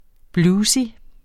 Udtale [ ˈbluːsi ]